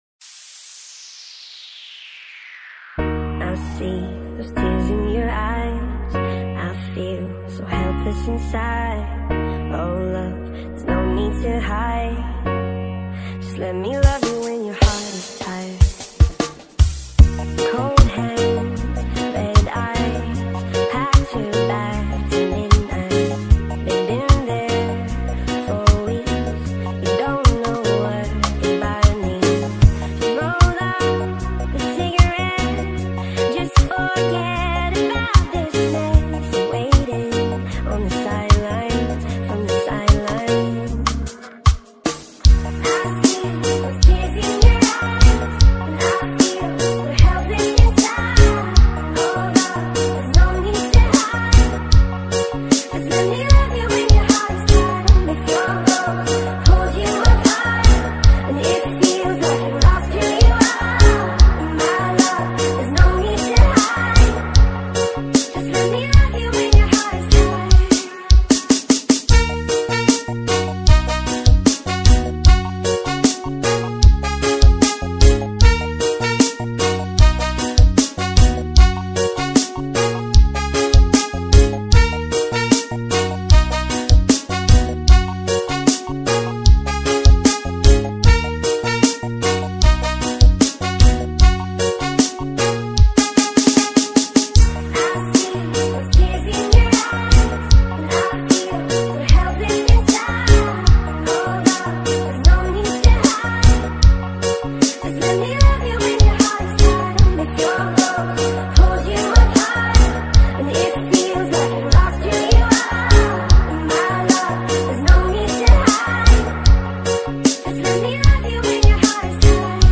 2021 REGGAE DO MARANHÃO - TIRED